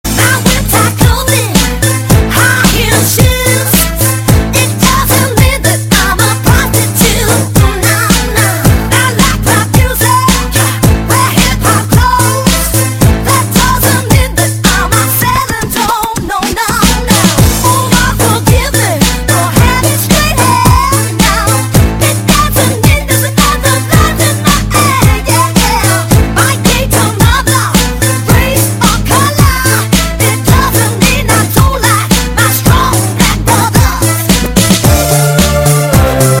异域风情DJ